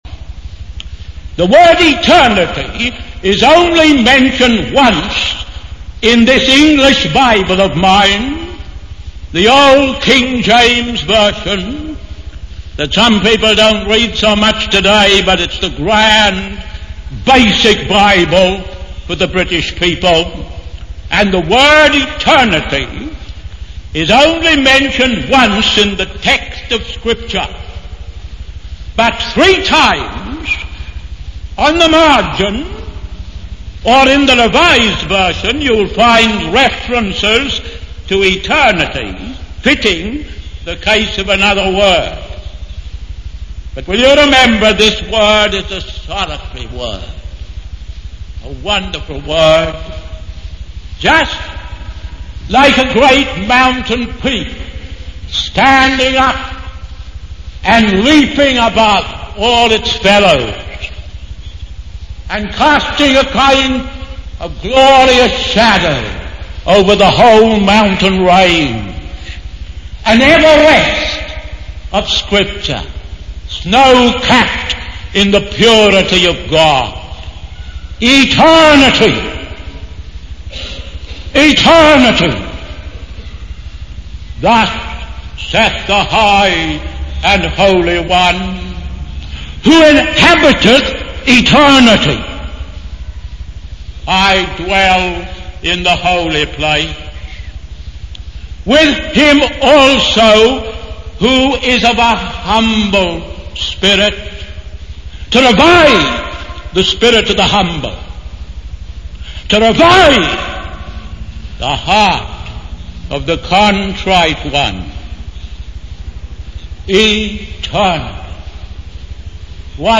In this sermon, the preacher emphasizes the importance of understanding and embracing the concept of eternity.